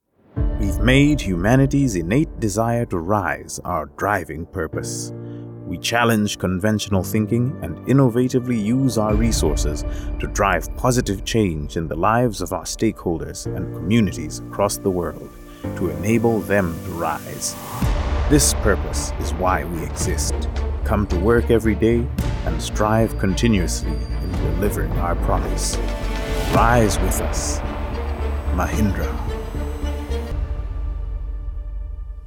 Nigerian Voice Artist
a talented Nigerian English voice artist, delivers warm, engaging, and clear performances for commercials, narration, Corporate, Explainers and audiobooks.....